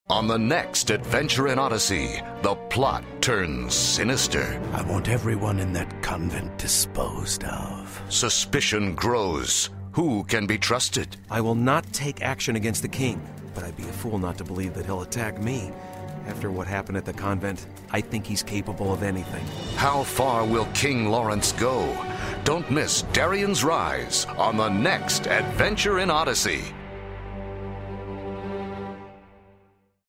Notes: This audio dramatization is based on Darien's Rise from the Adventures in Odyssey Passages book series.